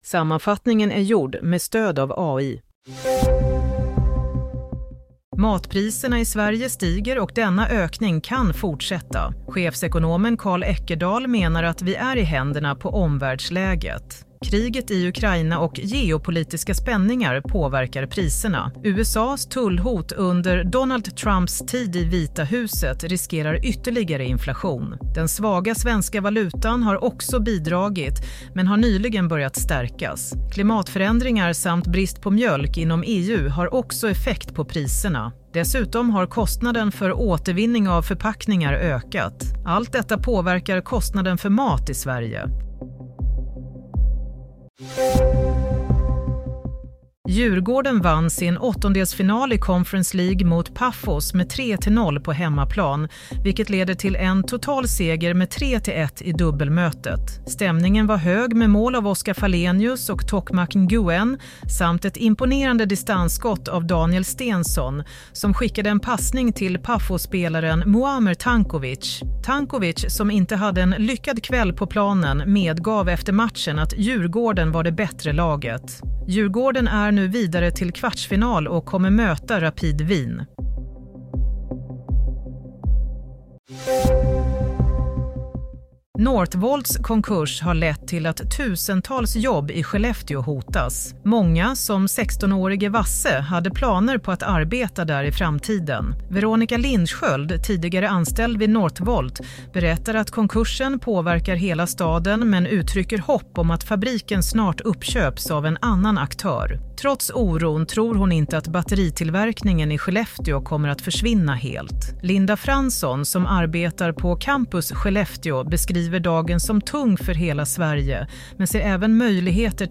Nyhetssammanfattning - 13 mars 22.00